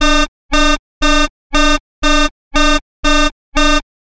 science_fiction_computer_console_alarm
Tags: Sci Fi Play